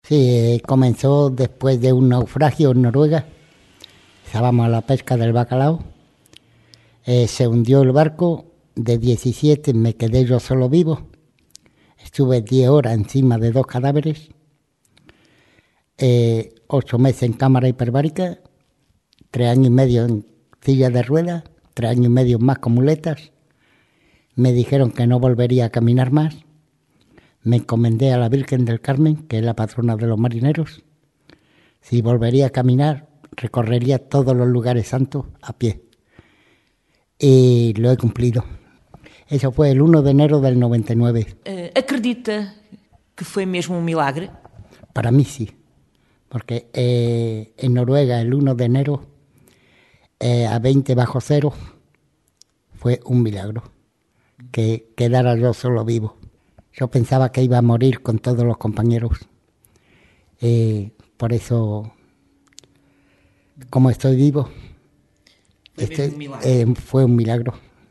Ficou hospedado no Albergue São Bento e foi lá que estivemos à conversa